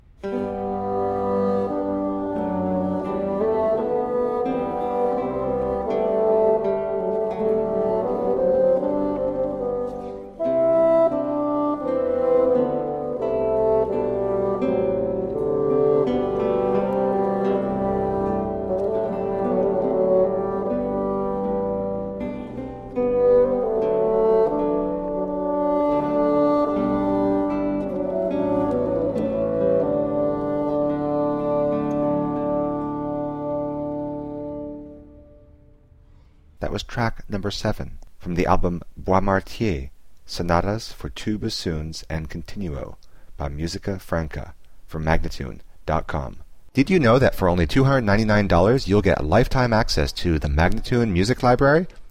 Classical, Chamber Music, Baroque, Instrumental, Bassoon
Harpsichord, Organ